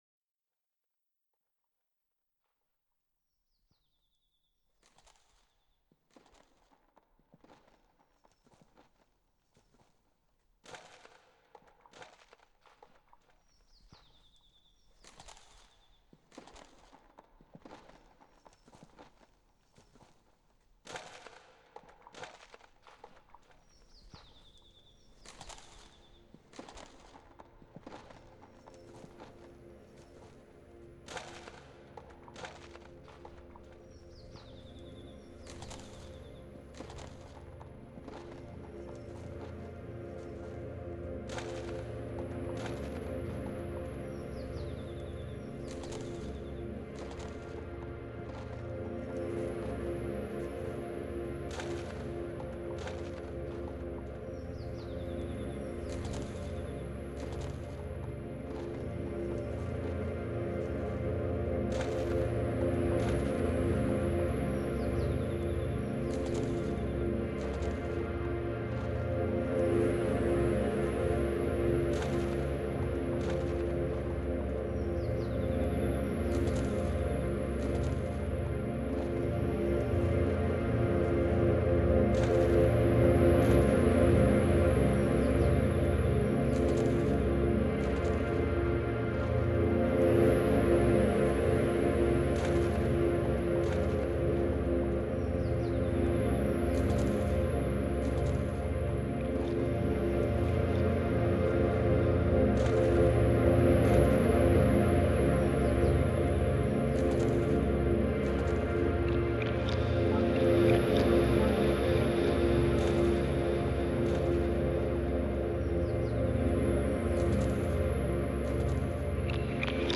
Genre: Ambient/Dub Techno.